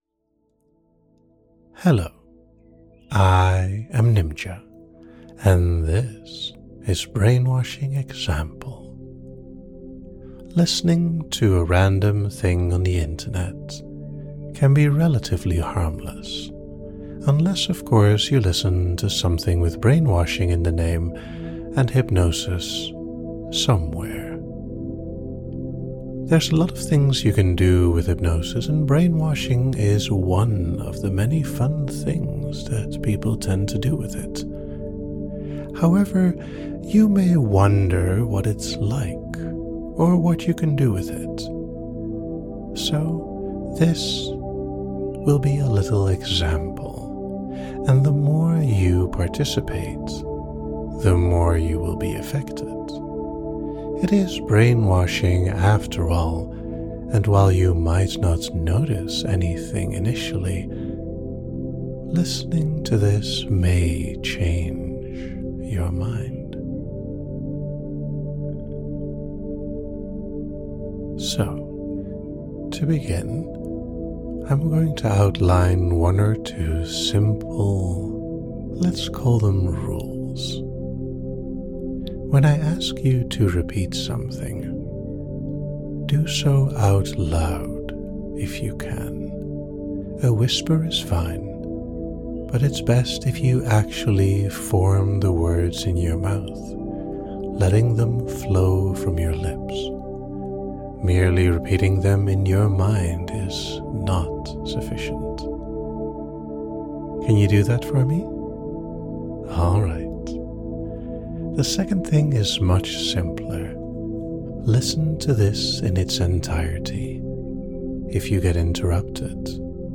Type Gentle Length 18:15 Categories Induction, Effect Features Participation, Amnesia Like it?